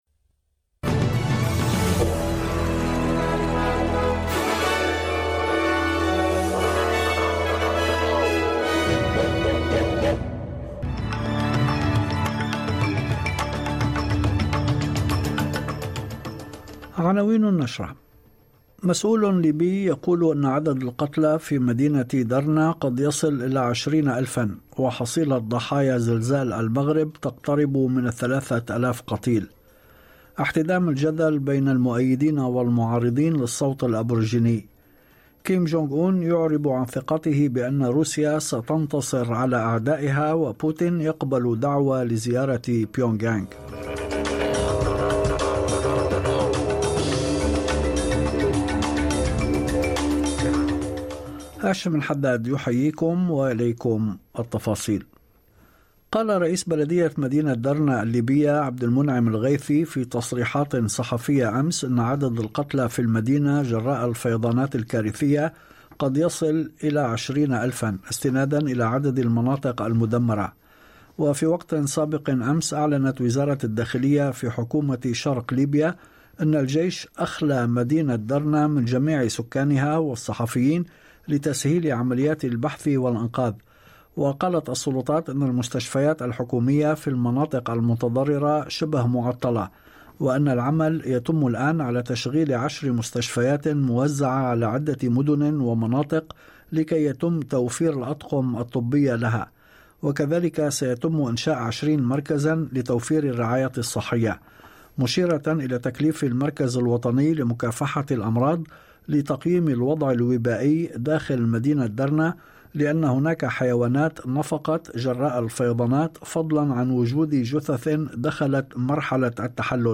نشرة أخبار المساء 14/9/2023
يمكنكم الاستماع الى النشرة الاخبارية كاملة بالضغط على التسجيل الصوتي أعلاه.